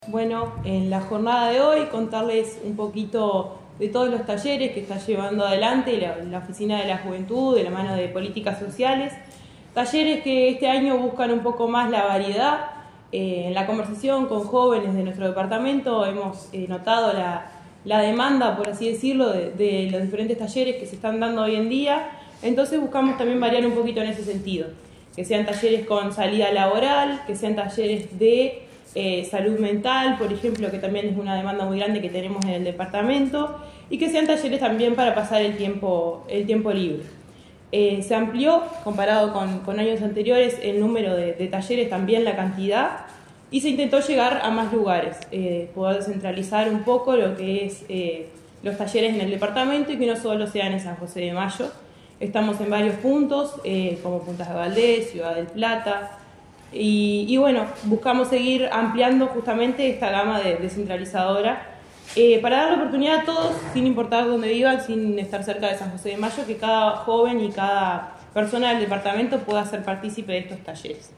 en la ex estación de AFE.